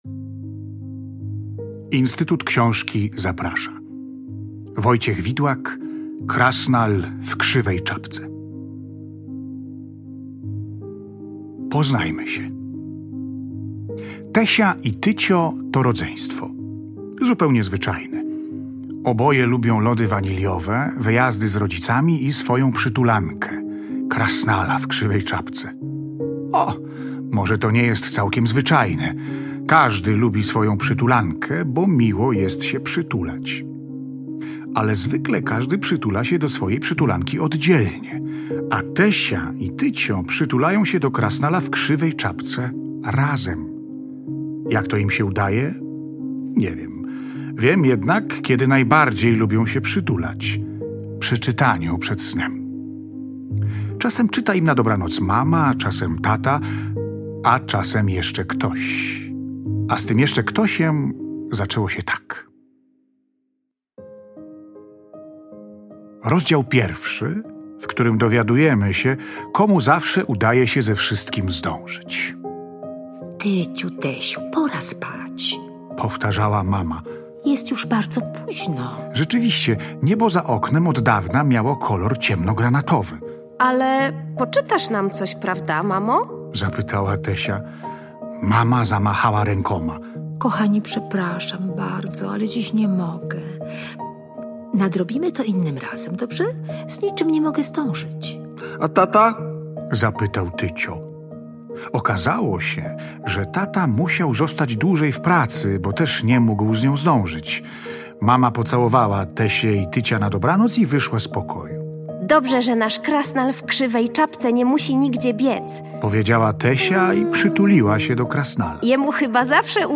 Właśnie z myślą o najmłodszych czytelnikach i ich rodzicach Instytut Książki we współpracy z Radiem Kraków przygotował niezwykły prezent – audiobook na podstawie książki „Krasnal w Krzywej Czapce” Wojciecha Widłaka z ilustracjami Aleksandry Krzanowskiej. To nie tylko wspaniała historia, ale również doskonała jakość produkcji, która zachwyca muzyką, efektami dźwiękowymi i mistrzowską grą aktorską.